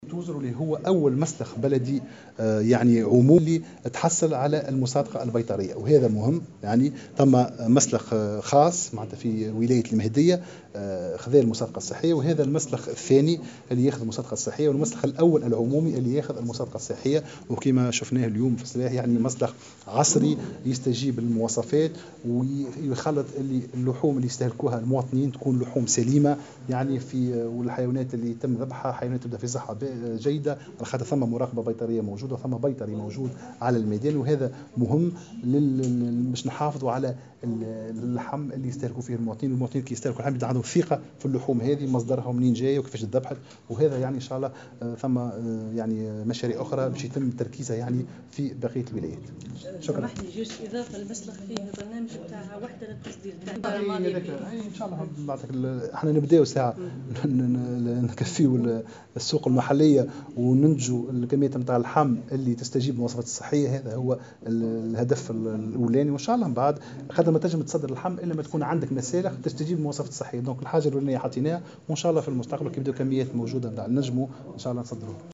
و أكد وزير التجارة عمر الباهي لمراسل "الجوهرة أف أم" بالجهة أن المسلخ الجديد هو أول مسلح عمومي في الجمهورية متحصل على المصادقة البيطرية بمواصفات صحية.